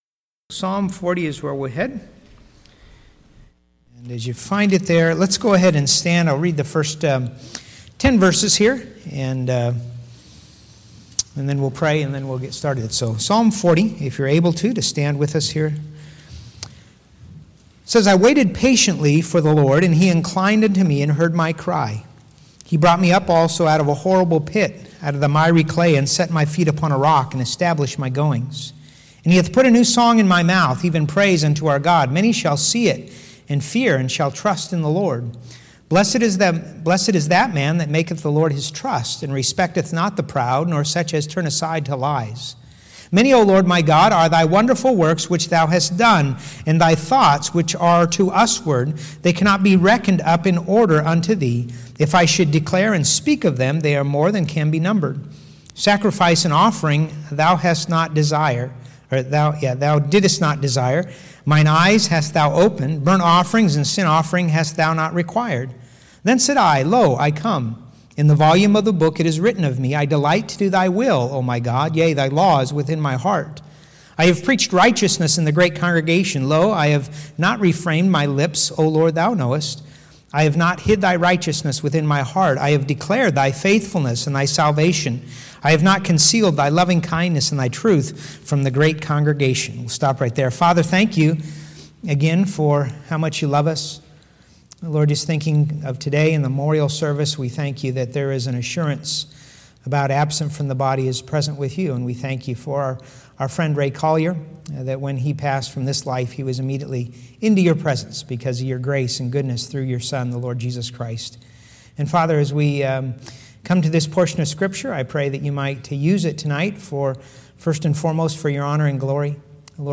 Sunday PM